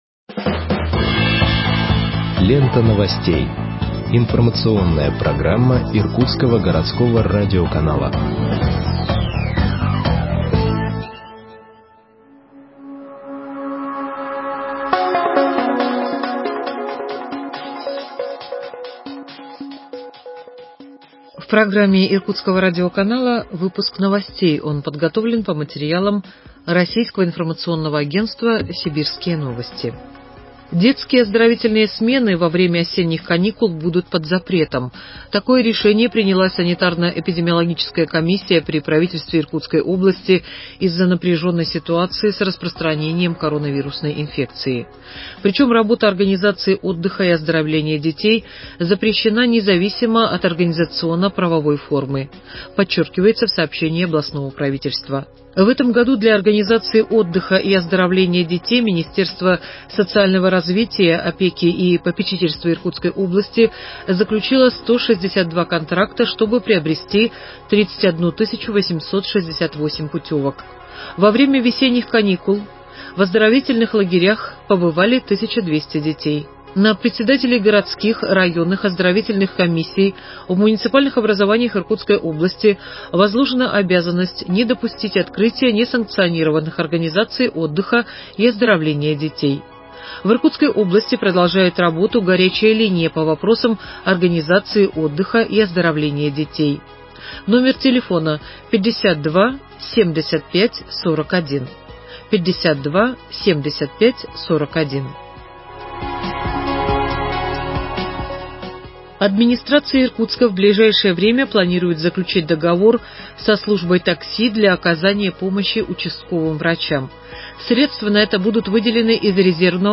Выпуск новостей в подкастах газеты Иркутск от 29.10.2020 № 1